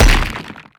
poly_explosion_stone.wav